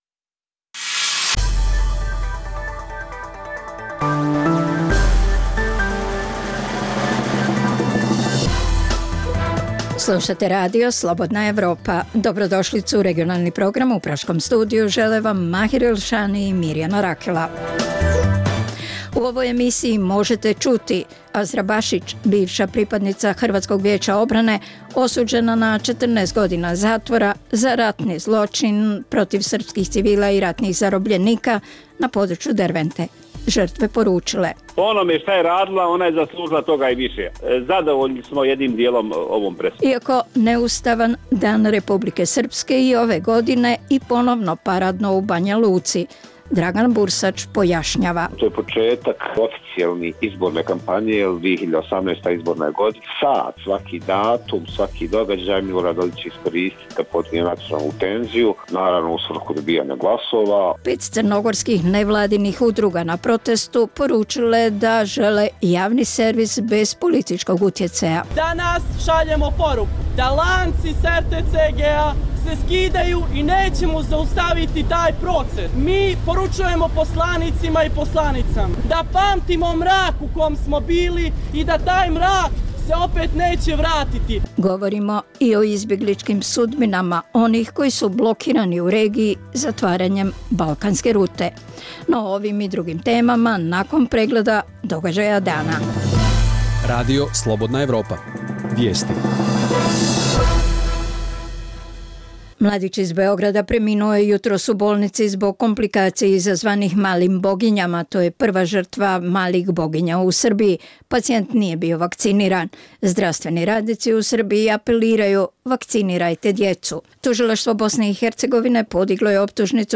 Dnevna informativna emisija Radija Slobodna Evropa o događajima u regionu i u svijetu.